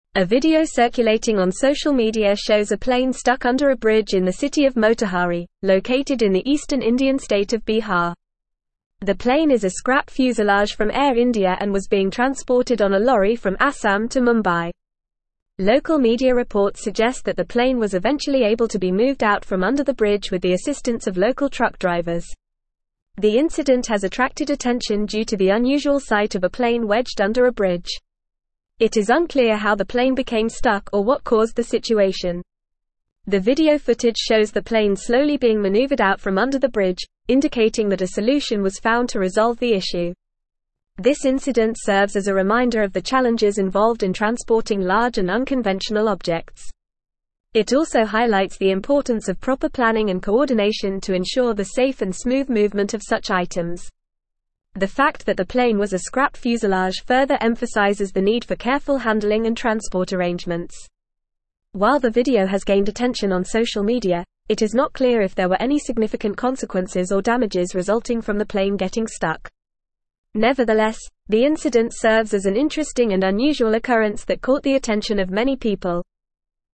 Fast
English-Newsroom-Advanced-FAST-Reading-Plane-gets-stuck-under-bridge-in-India.mp3